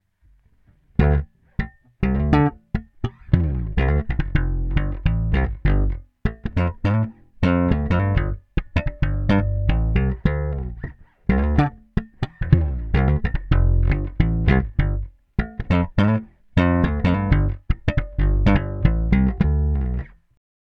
If your looking for a large full warm tone from your bass these are the pickups.
Super Neo – Slap – Tone Up
Super-Neo-Neck-Slap-Tone-UP.m4a